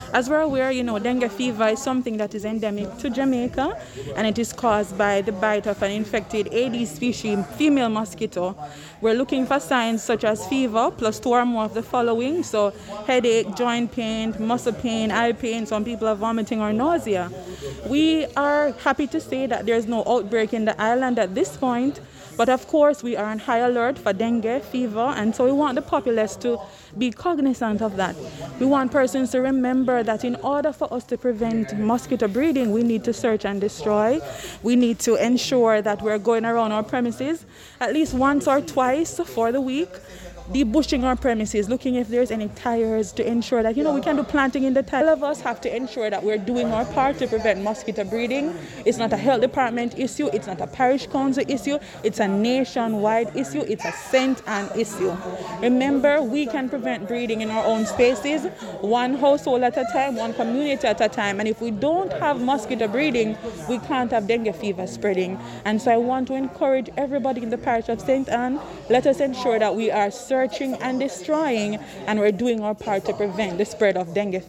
Dr Tamika Henry Medical Officer  of Health
She spoke to the Times after the recent St Ann Municipal Corporation Meeting.